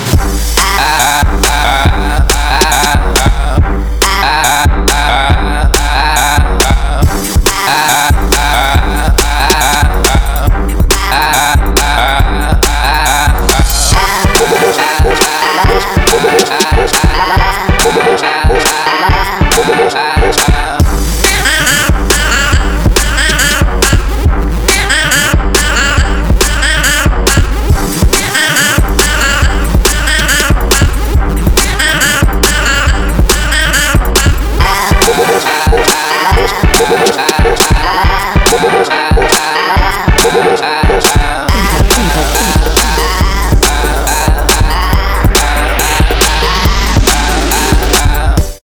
• Качество: 320, Stereo
веселые
без слов
Trap